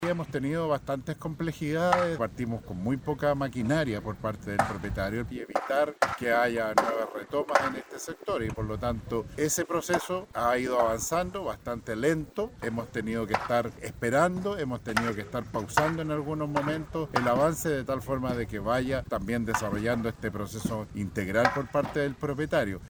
El Delegado Presidencial Regional de Valparaíso, Yanino Riquelme, efectuó un balance de la primera semana de desalojos y demolición, emplazando a los propietarios por la tardanza en la disposición de maquinaria y en el cierre perimetral.